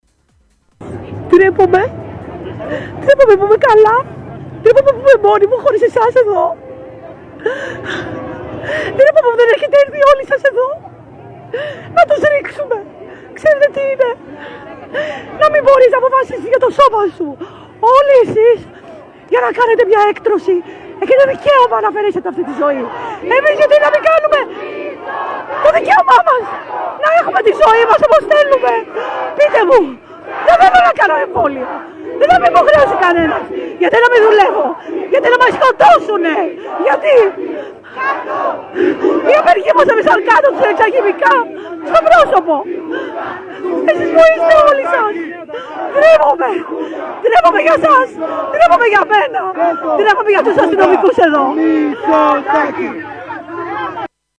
Νεα διαμαρτυρία απο τους Υγειονομικούς εξω απο τη Βουλη σήμερα 30/03/22 και ο ΜΗΤΣΟ - ΘΑΦΤΗΣ με ΠΛΕΥΡΗ και ΓΚΑΓΚΑ εστειλαν τα ΜΑΤ να τους ρίξουν ΧΗΜΙΚΑ!!
υγιειονομικος-βουλη.mp3